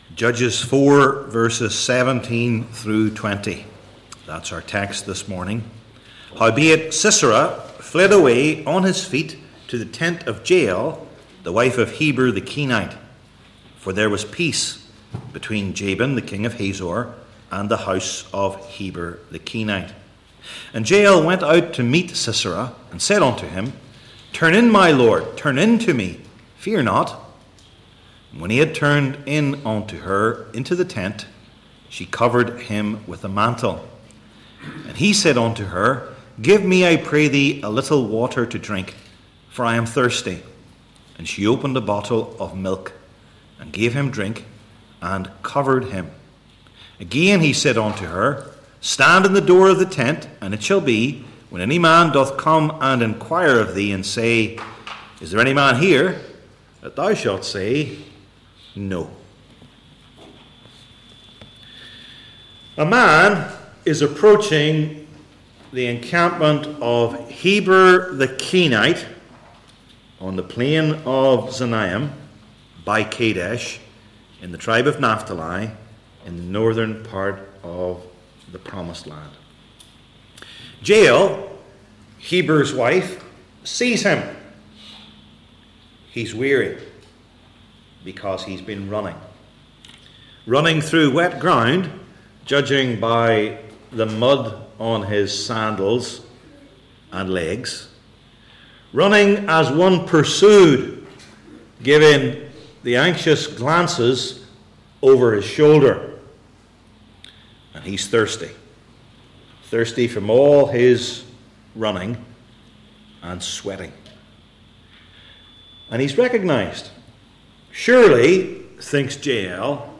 Judges 4:17-20 Service Type: Old Testament Sermon Series I. The Unlikely Deliverer II.